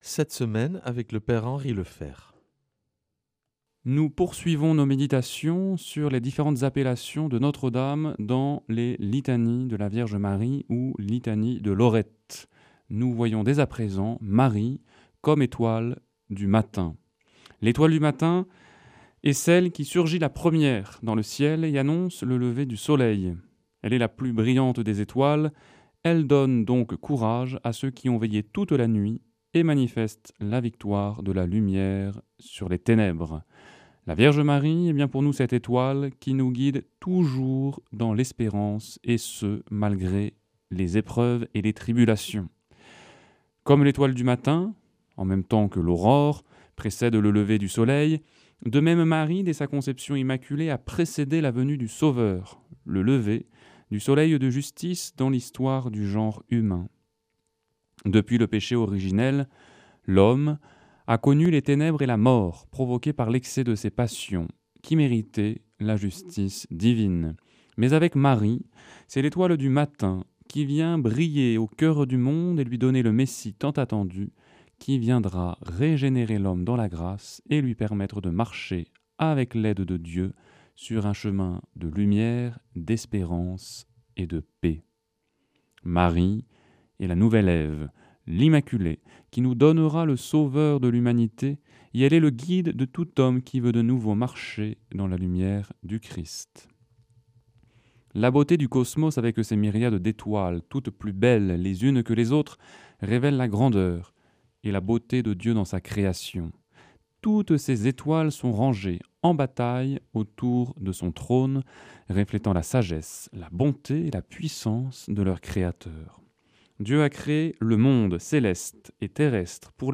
lundi 11 août 2025 Enseignement Marial Durée 10 min